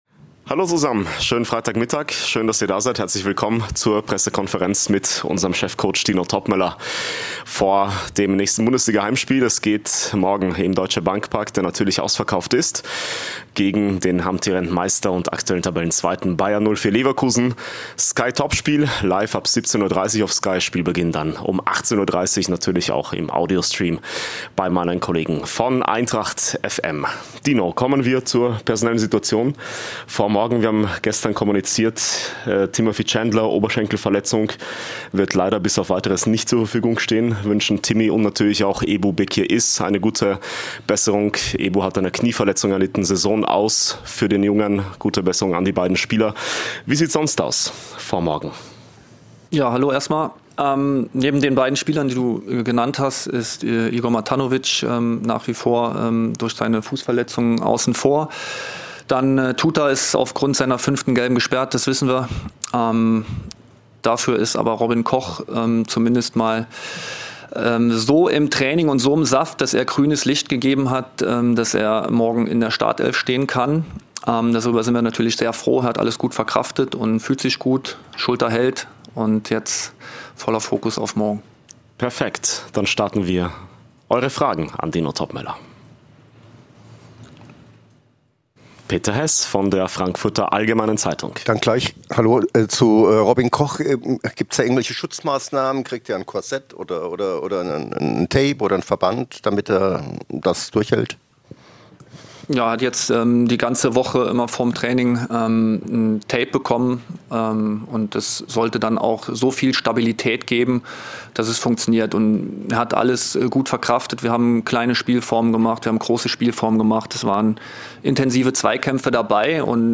Die Pressekonferenz mit Cheftrainer Dino Toppmöller vor dem Bundesliga-Heimspiel gegen Bayer Leverkusen.